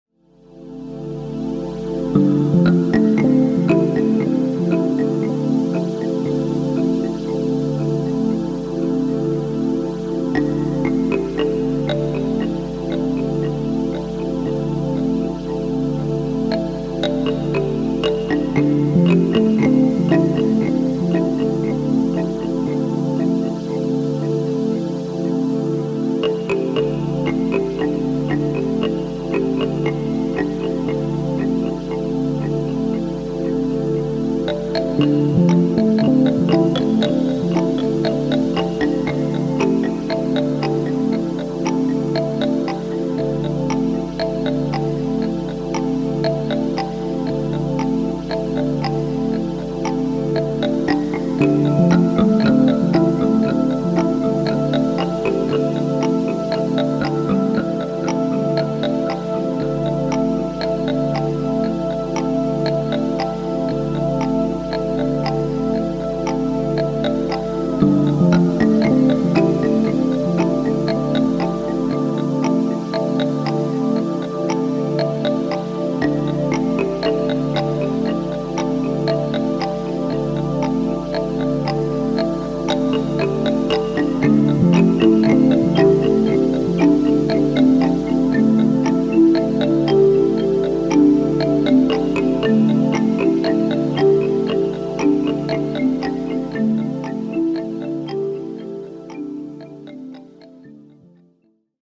Genre Ambient , Breakbeat , Electronic , IDM